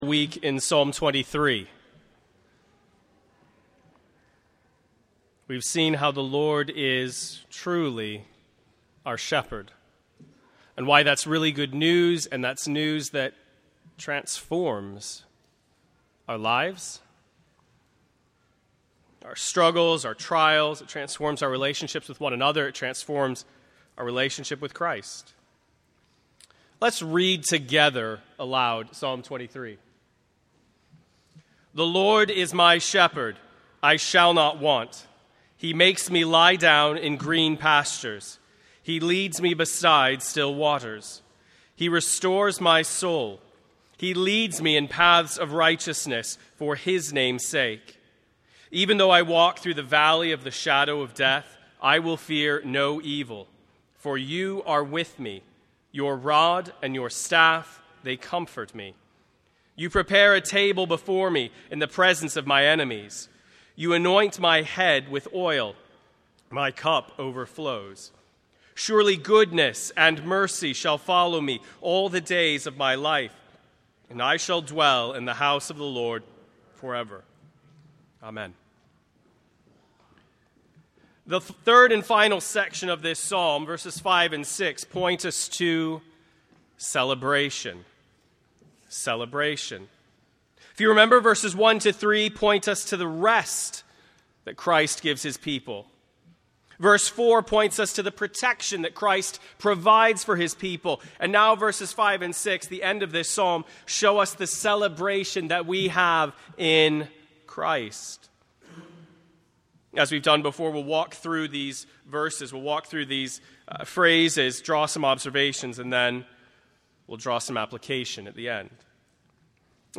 Bloomington Bible Church Sermons